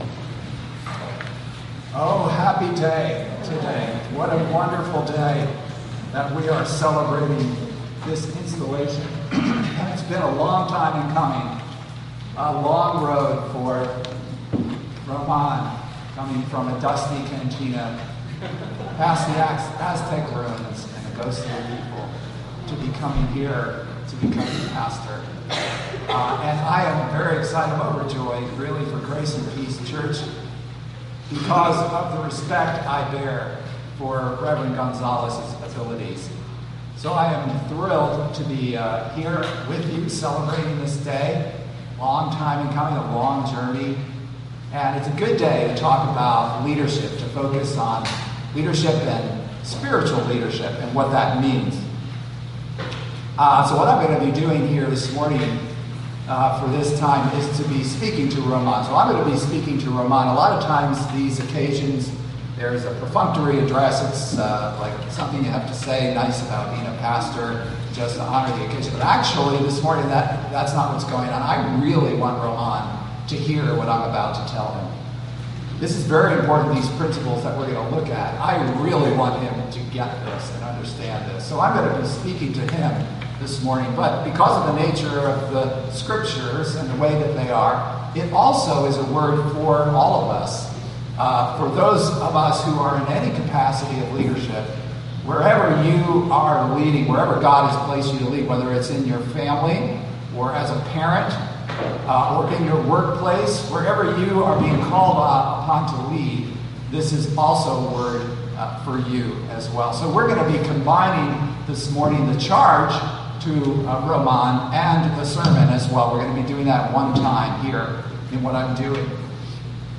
Installation Service
Sermon